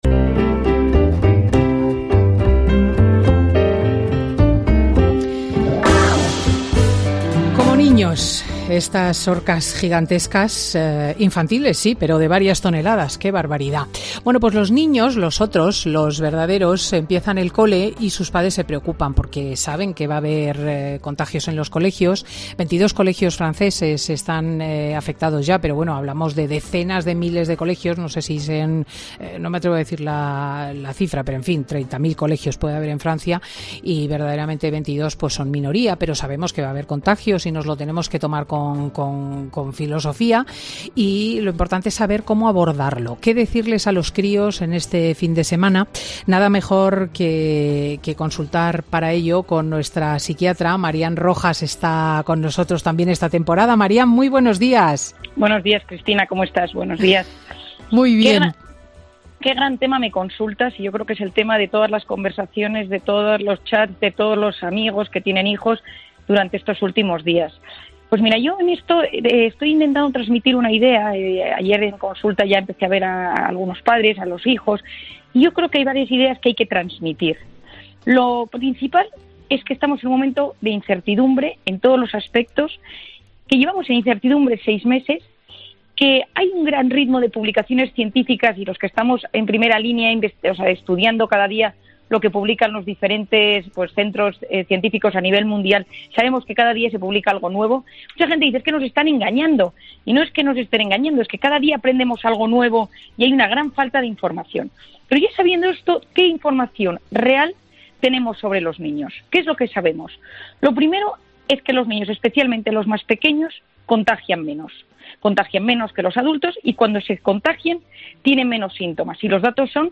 Nuestra psiquiatra nos explica en Fin de Semana con Cristina cómo debemos hacer con los niños para que el regreso a las aulas no sea especialmente duro